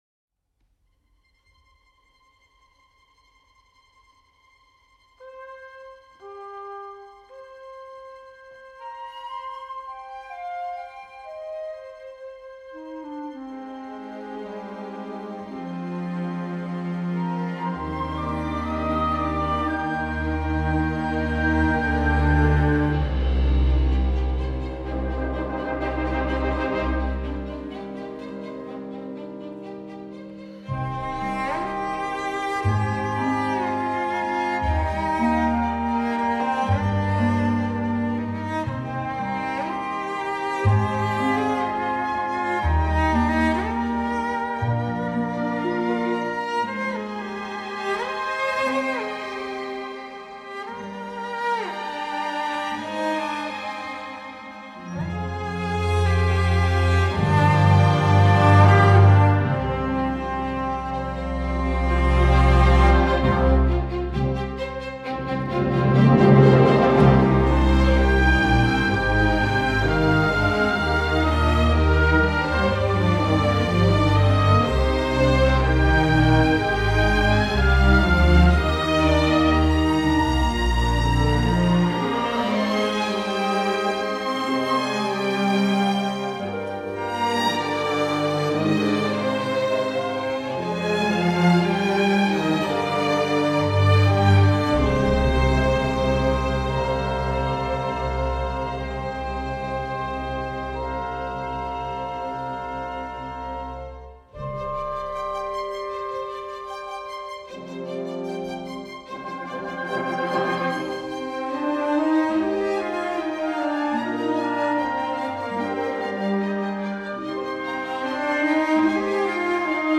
Hero’s Theme: Tragic, Emotional, Bittersweet